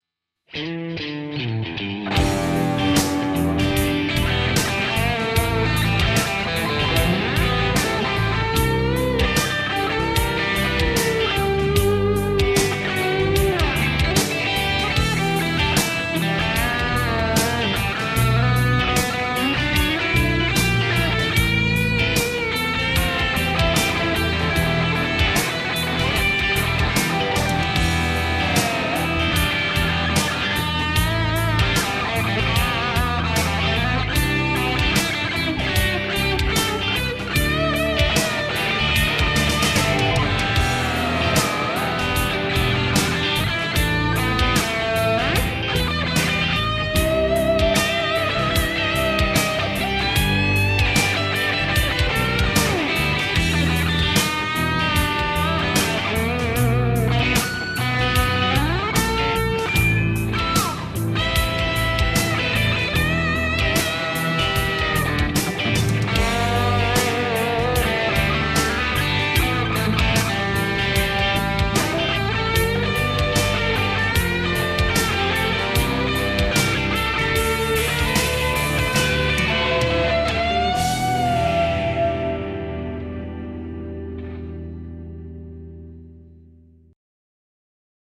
- Soita annetun taustan päälle solistinen osuus valitsemallasi instrumentilla
energia maisemointia, tämmöistä aina tarvitaan
Voimakkaalla soundilla väkevää ilmaisua.Tyylikkäitä kohtia, mm alkupuolen kampivibralopetus fraasille. Paikoin aika täyteen soitettu.
Valittavaa tunnelmaa, miks mulle käy aina näin.
melkosen raju meininki ja soundi ++